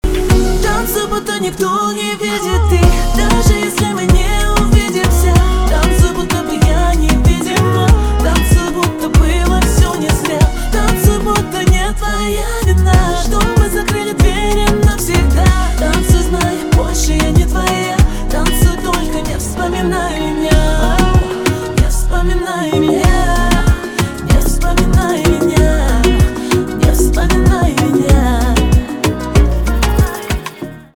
поп
гитара
грустные